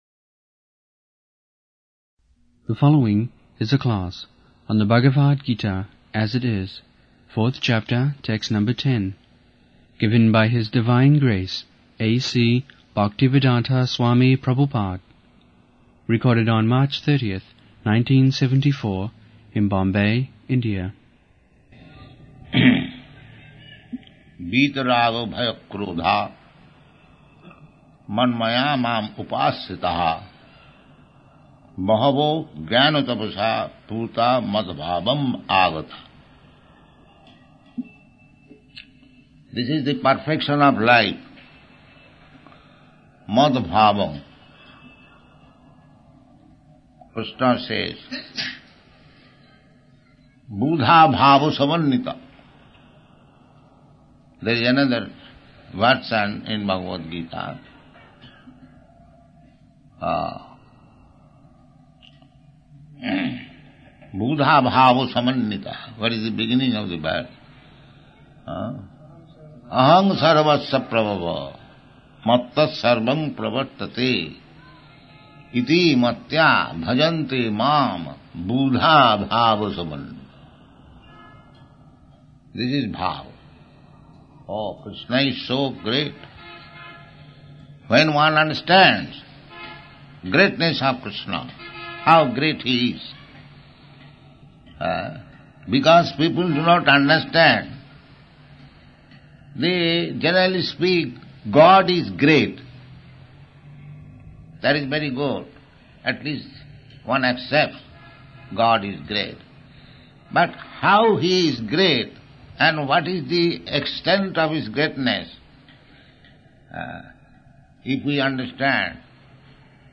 74/03/30 Bombay, Bhagavad-gita 4.10 Listen